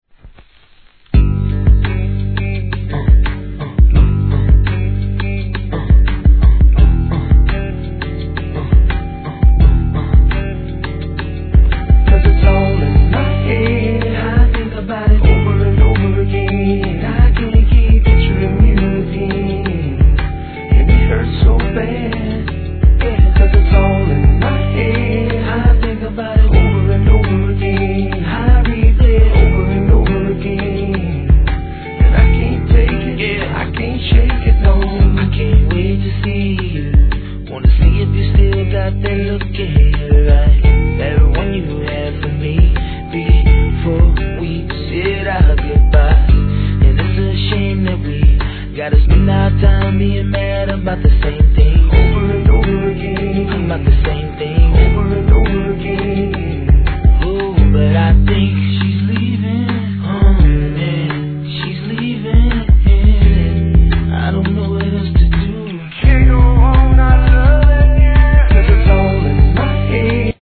1. HIP HOP/R&B
穏やかなメロディー・ライン が心地よい売れ線!